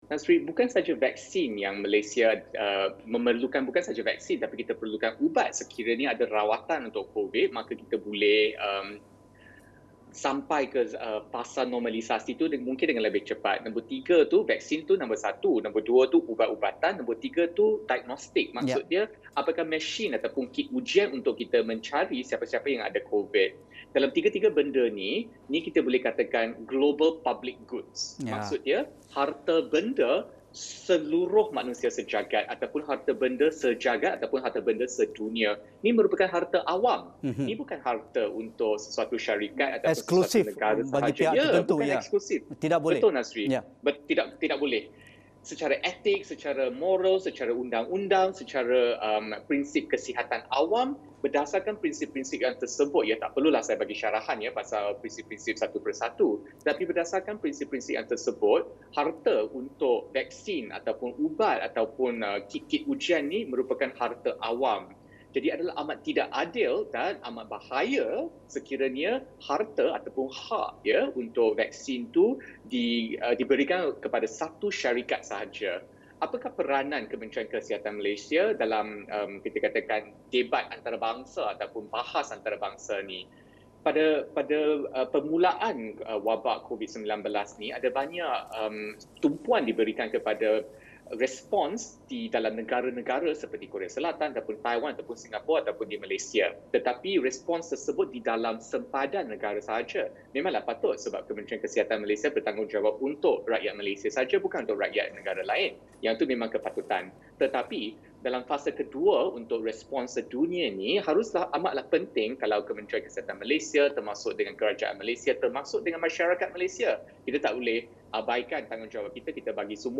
Penerangan terperinci Pakar Kesihatan Awam